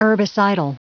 Prononciation du mot herbicidal en anglais (fichier audio)
Prononciation du mot : herbicidal